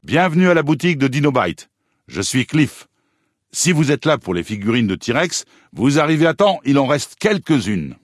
Cliff Briscoe souhaitant la bienvenue à la Boutique du Dino Bite dans Fallout: New Vegas.
FNV_Cliff_Briscoe_bienvenue.ogg